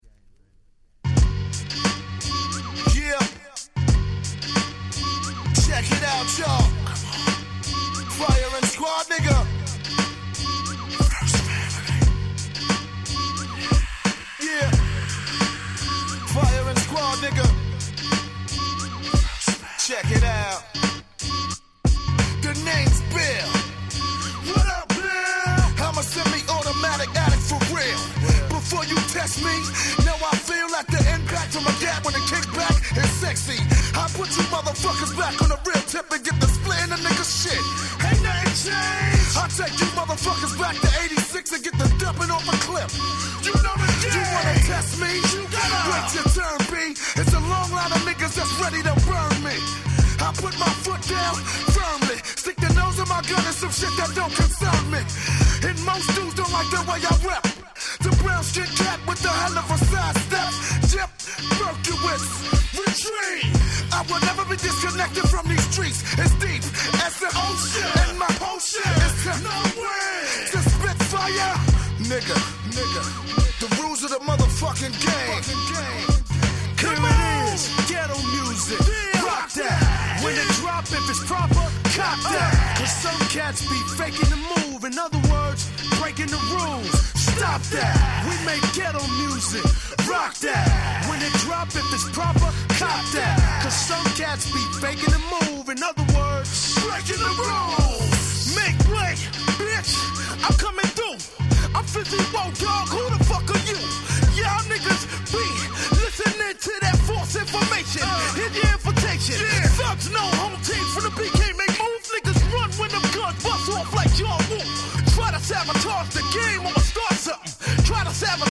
98' Smash Hit Hip Hop LP !!